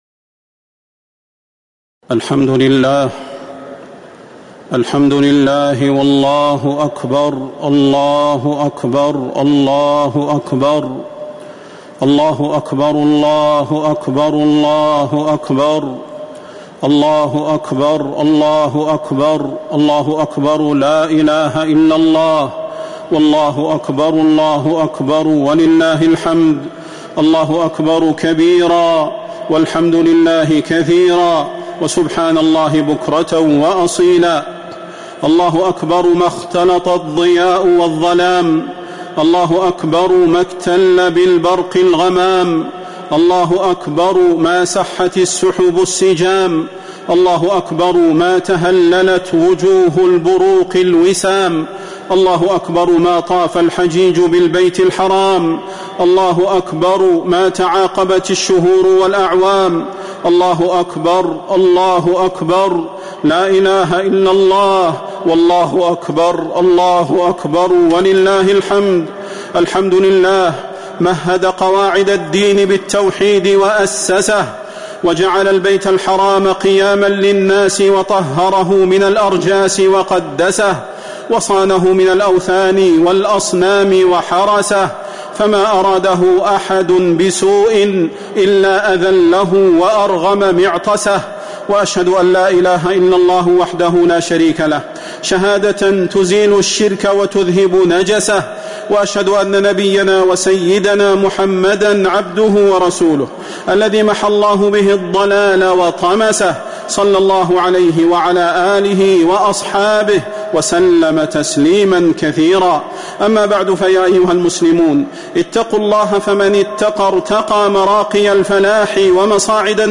خطبة عيد الأضحى - المدينة - الشيخ صلاح البدير
تاريخ النشر ١٠ ذو الحجة ١٤٤٦ هـ المكان: المسجد النبوي الشيخ: فضيلة الشيخ د. صلاح بن محمد البدير فضيلة الشيخ د. صلاح بن محمد البدير خطبة عيد الأضحى - المدينة - الشيخ صلاح البدير